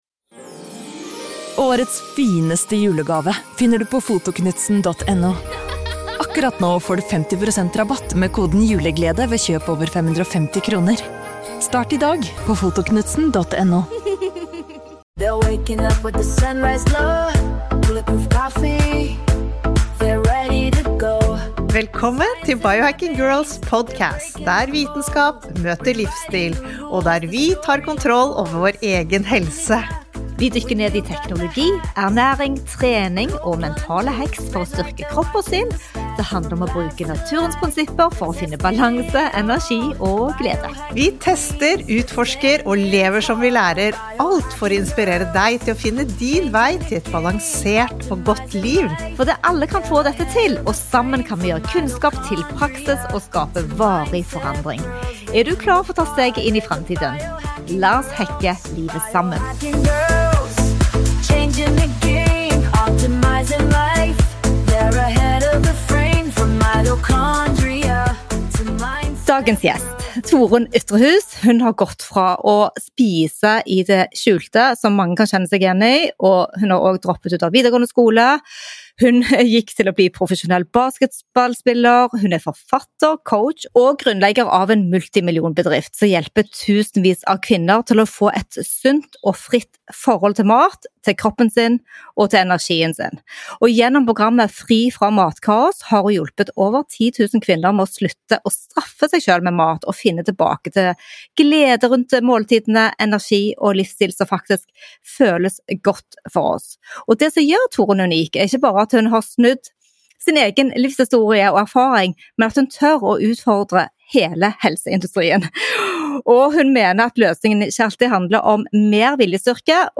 Dette er en samtale om veien fra kamp til balanse, fra selvstraff til selvrespek tog hvordan du kan bygge både en kropp og et liv som føles godt.